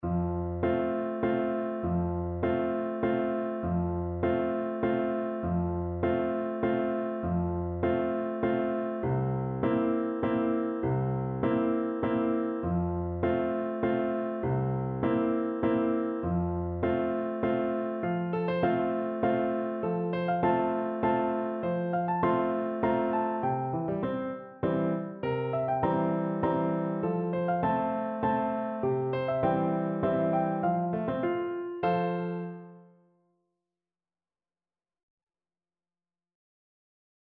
Moderato
C5-C6
3/4 (View more 3/4 Music)
Traditional (View more Traditional Voice Music)
world (View more world Voice Music)